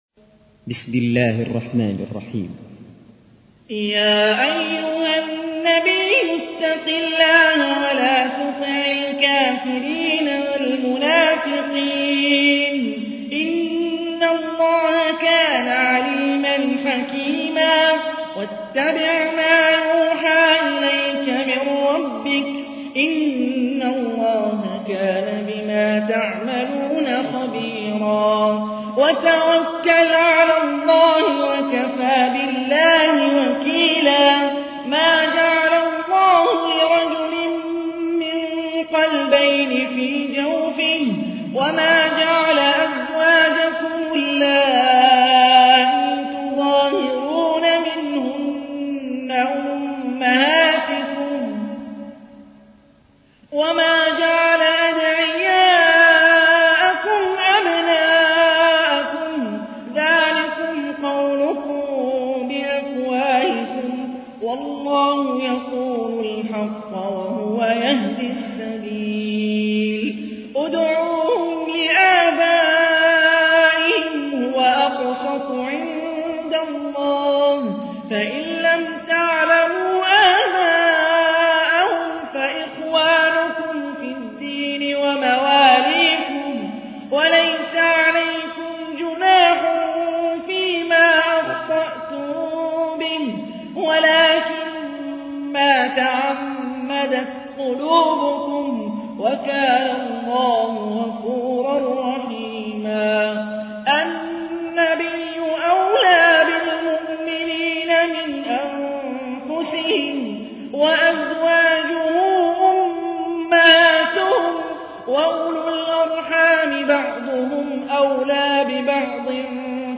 تحميل : 33. سورة الأحزاب / القارئ هاني الرفاعي / القرآن الكريم / موقع يا حسين